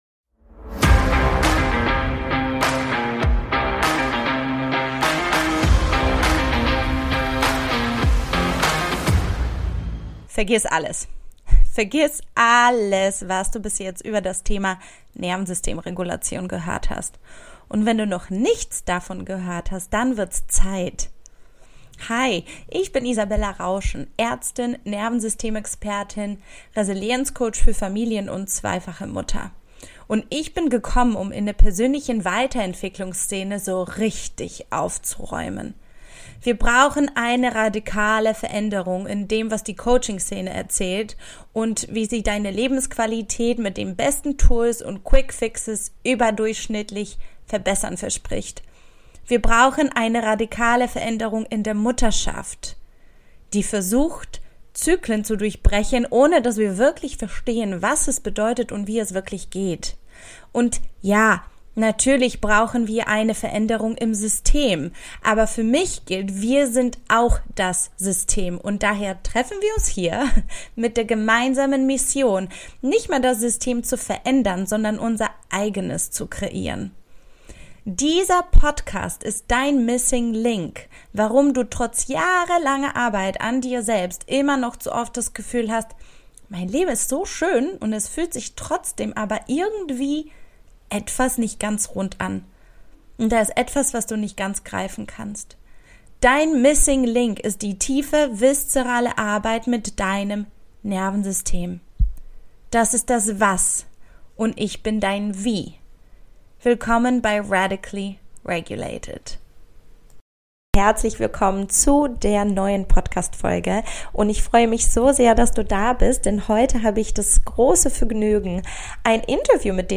In der heutigen Podcastfolge habe ich das Vergnügen ein wunderbares Interview mit dir zu teilen.